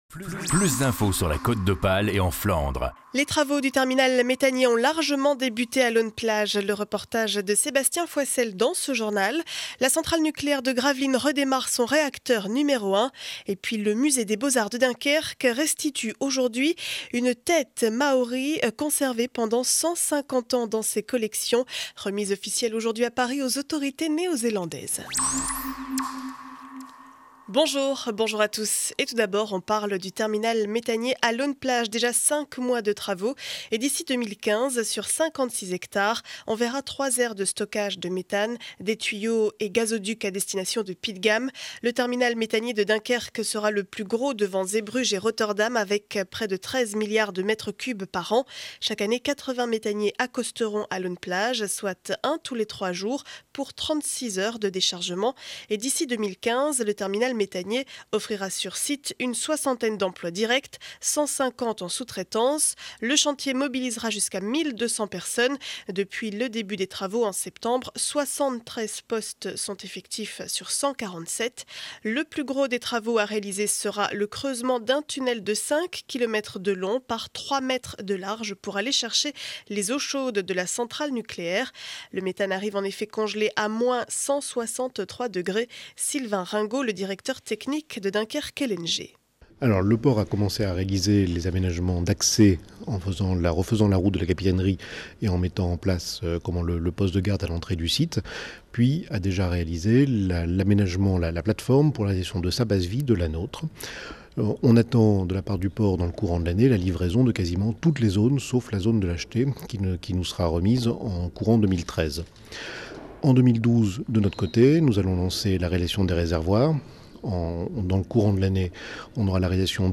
Journal du lundi 23 janvier 2012 7 heures 30 édition du Dunkerquois.